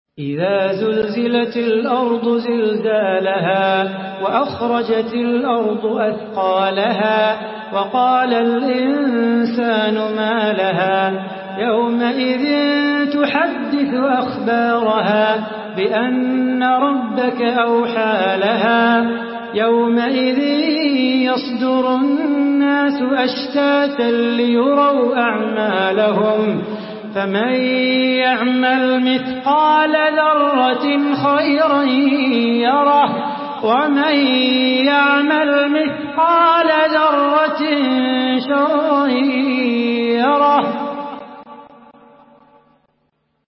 سورة الزلزلة MP3 بصوت صلاح بو خاطر برواية حفص
مرتل